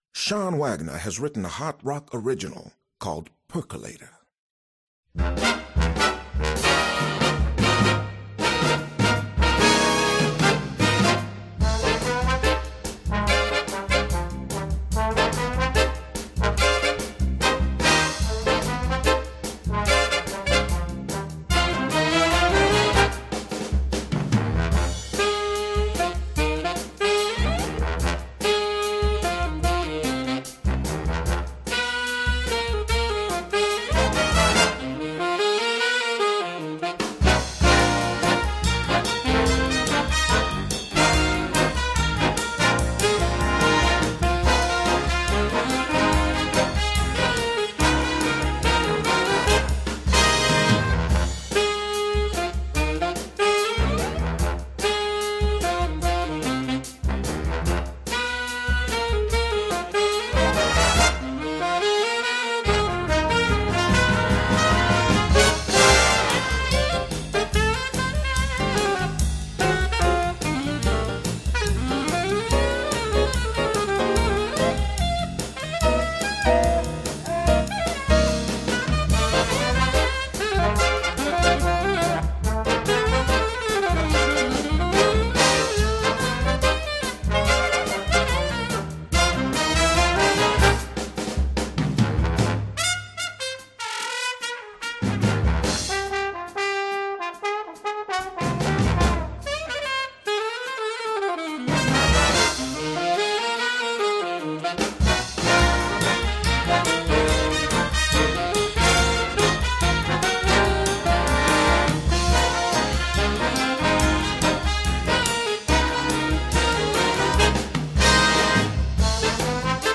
Voicing: Jazz Ensemble